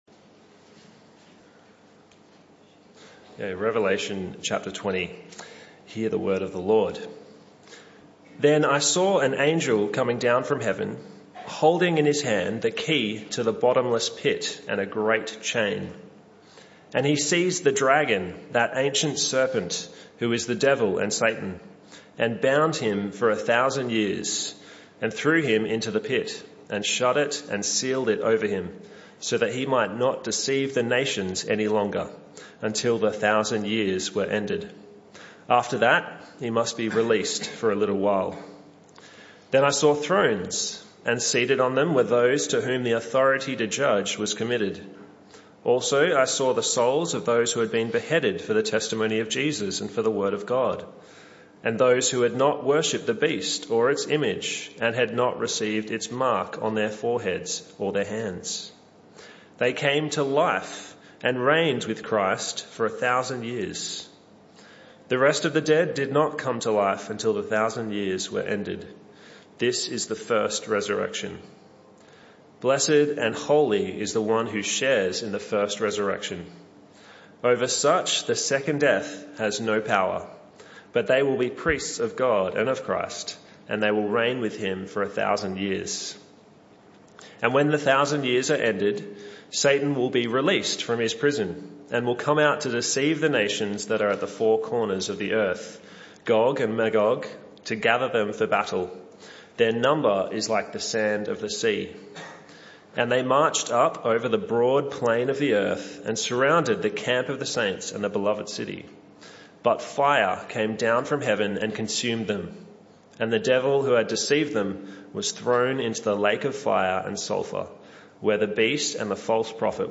This talk was part of the AM Service series entitled Holding Fast In Hostiles Times (Talk 23).